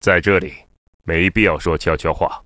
文件 文件历史 文件用途 全域文件用途 Bk_tk_01.ogg （Ogg Vorbis声音文件，长度2.5秒，109 kbps，文件大小：33 KB） 源地址:游戏语音 文件历史 点击某个日期/时间查看对应时刻的文件。